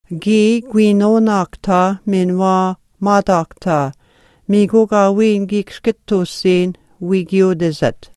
geese_16.mp3